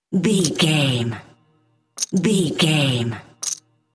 Casino 3 chips table x3
Sound Effects
foley